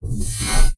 drone1.ogg